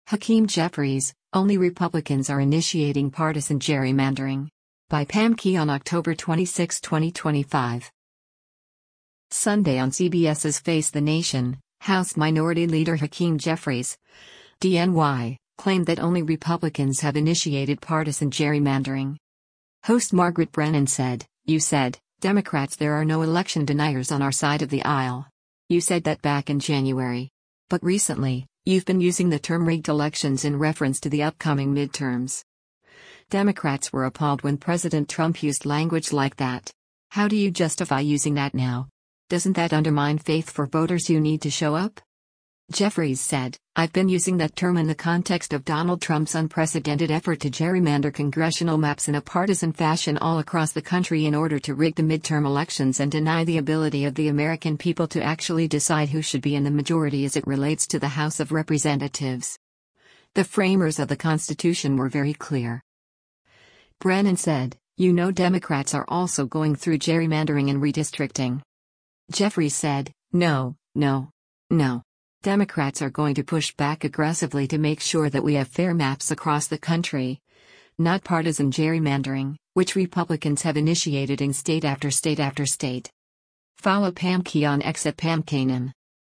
Sunday on CBS’s “Face the Nation,” House Minority Leader Hakeem Jeffries (D-NY) claimed that only Republicans have initiated “partisan gerrymandering.”